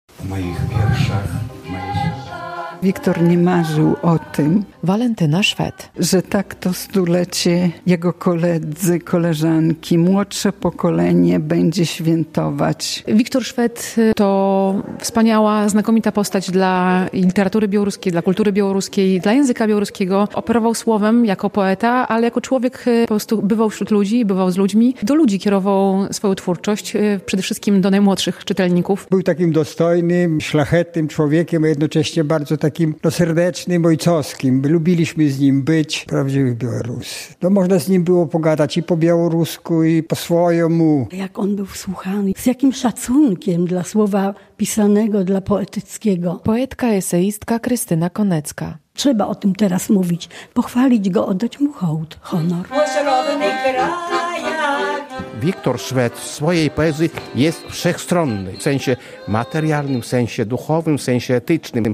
Przyjaciele, rodzina i czytelnicy spotkali się w sobotę (29.03) w Białymstoku, by wspólnie powspominać Wiktora Szweda. W tym roku przypada jubileusz 100-lecia urodzin jednego z najbardziej znanych białoruskich literatów z naszego regionu.
relacja